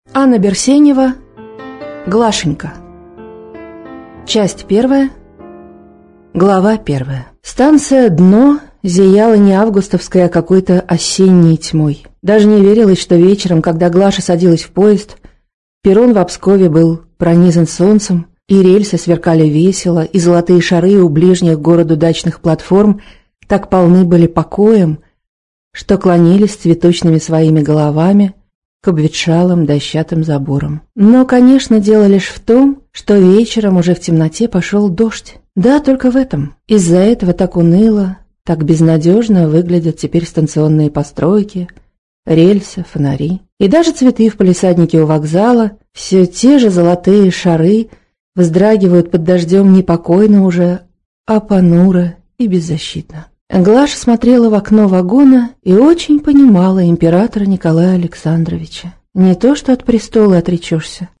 Аудиокнига Глашенька из жанра Роман, повесть - Скачать книгу, слушать онлайн